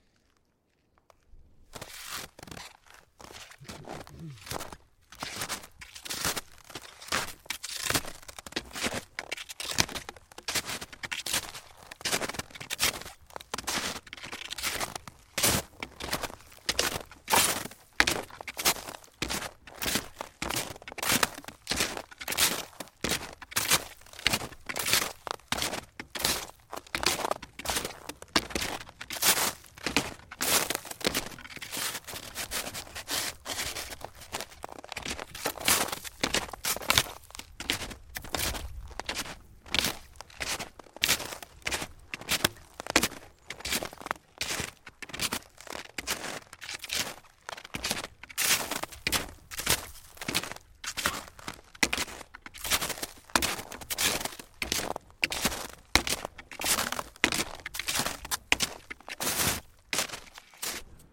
冬天" 脚步声 雪鞋 老木头2 挤满了雪的小路 速度慢 嘎吱嘎吱的 碎石子
描述：脚步雪鞋老wood2包装雪径慢速clacky crumbly.flac
Tag: 包装 脚步声 雪鞋 越野 雪地 wood2